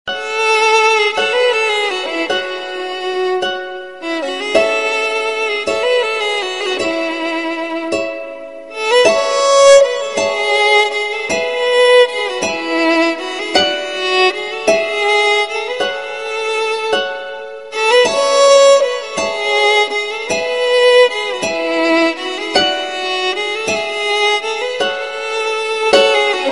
Suoneria Violino
Categoria Effetti Sonori